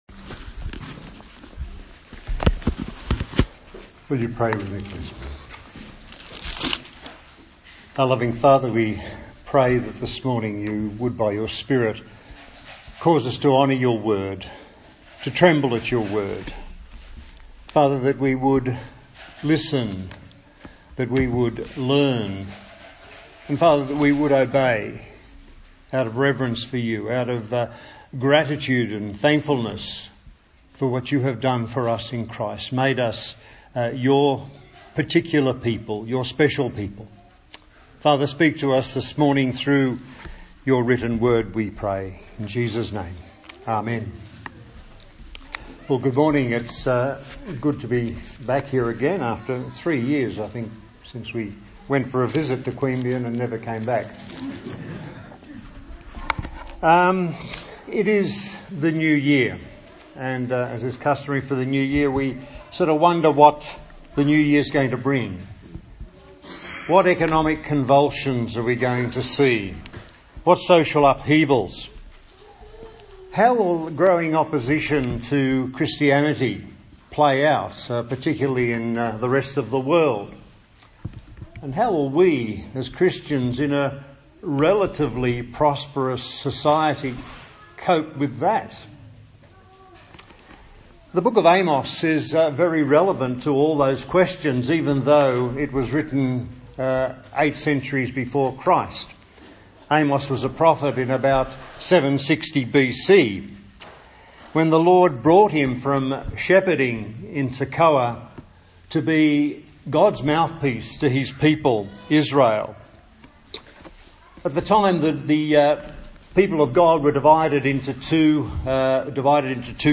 A sermon on the book of Amos
Service Type: Sunday Morning